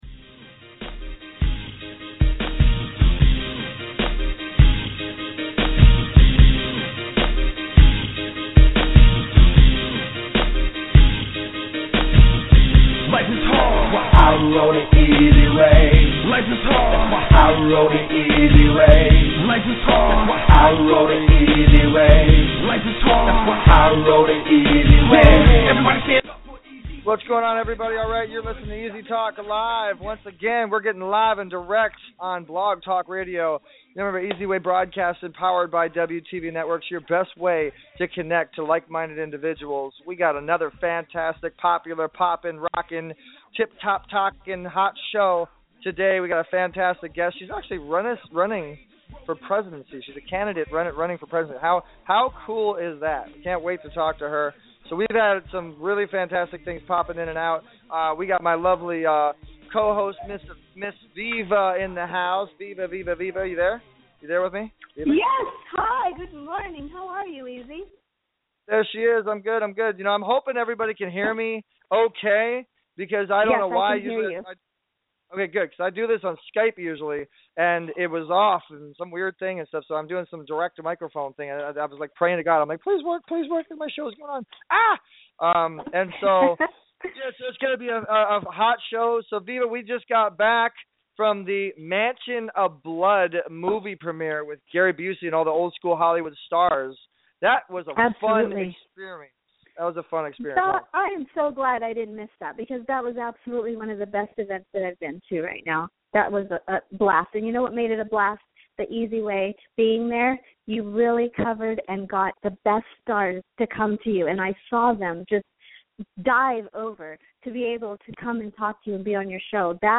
Politics EZ TALK LIVE